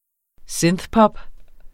Udtale [ ˈsenθˌpʌb ]